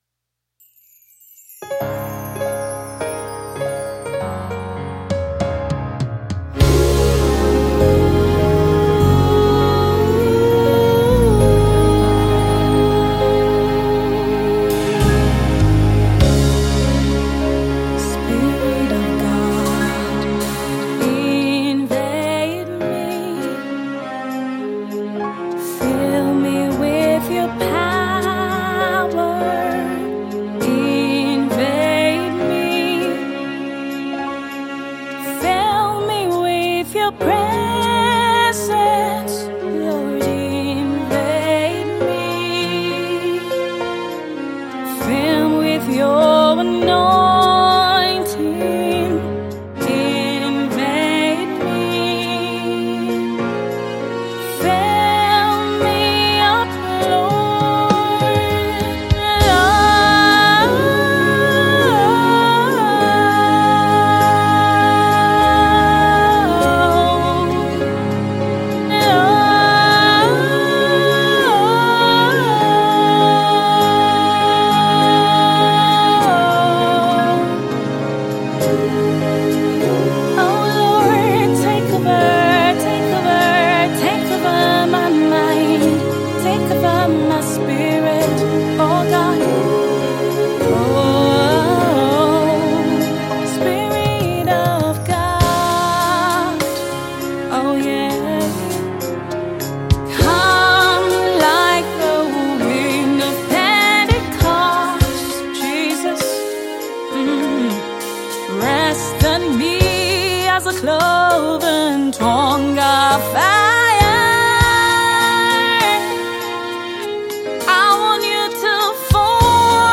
Her style of music is gospel contemporary.